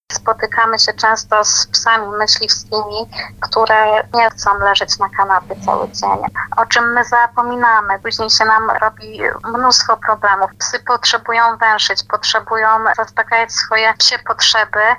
psia behawiorystka